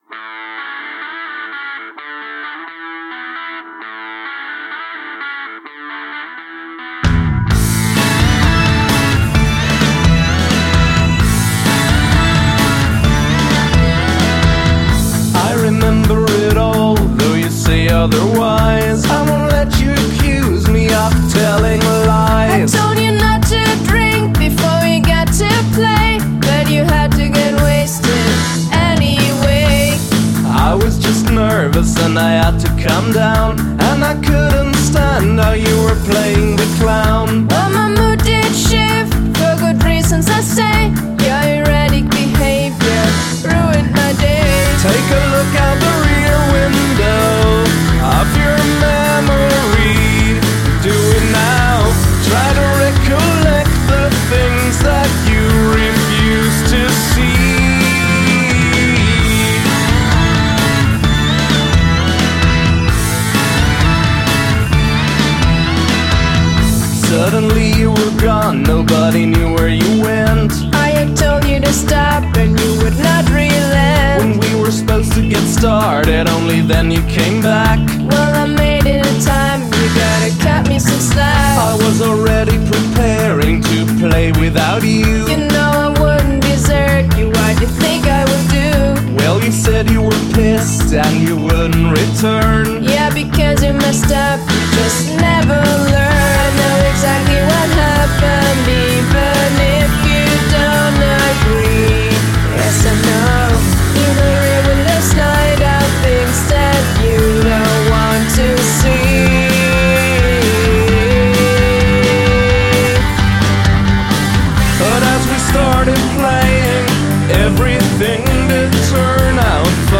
Sounds great, drums a bit muffled though.